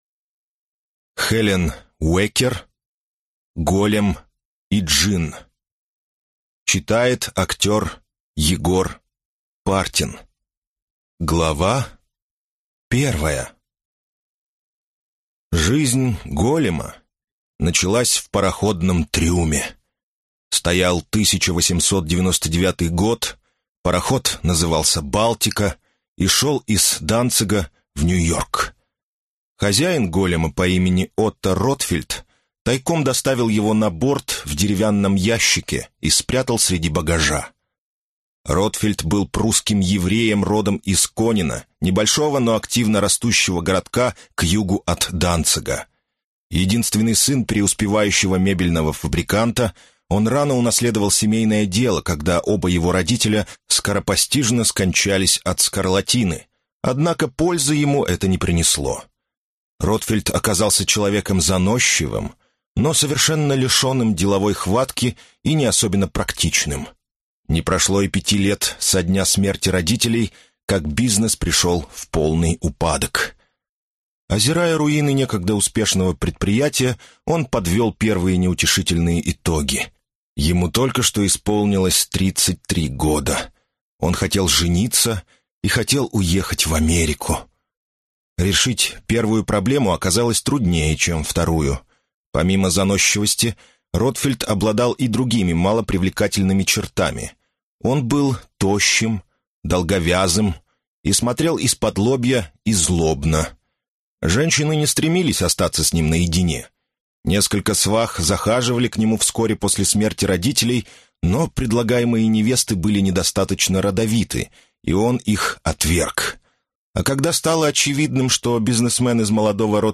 Аудиокнига Голем и Джинн | Библиотека аудиокниг